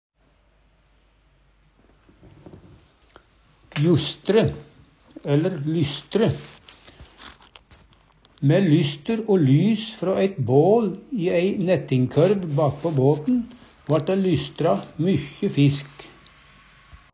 justre/lystre - Numedalsmål (en-US)